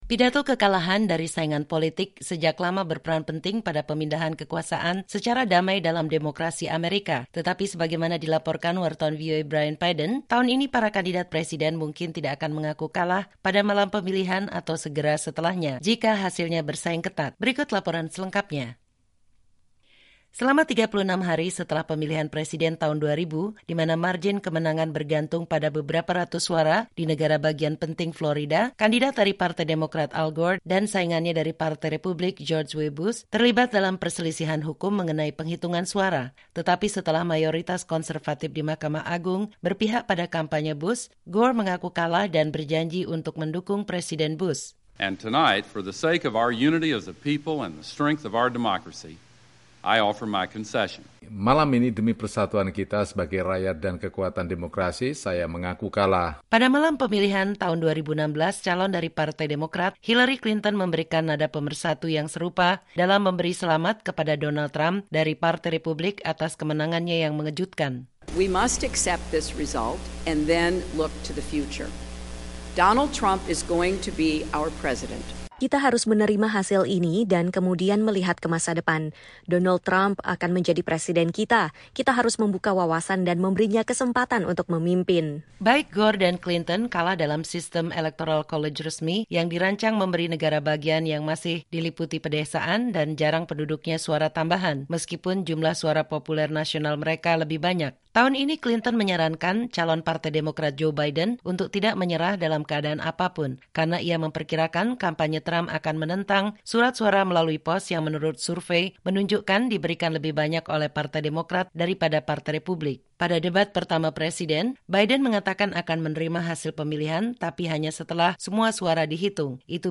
Pemilu Amerika